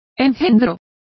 Complete with pronunciation of the translation of foetus.